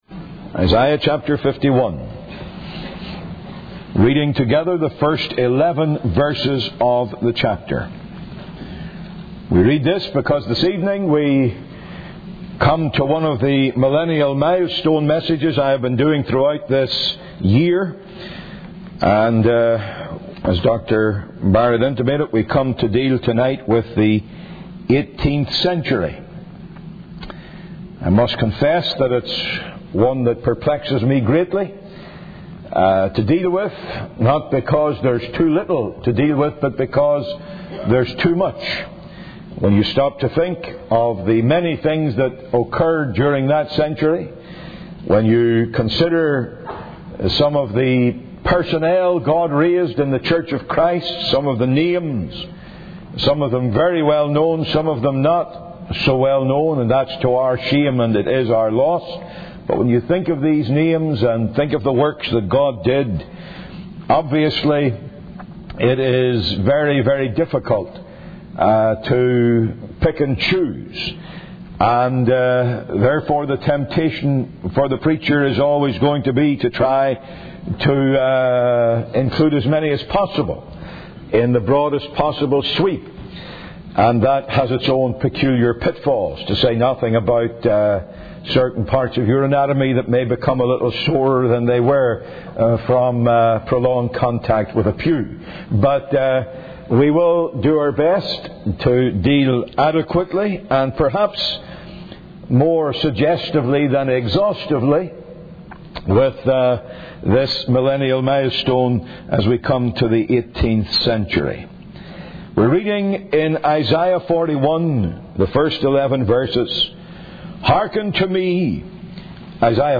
In this sermon, the preacher emphasizes the importance of preaching the word of God.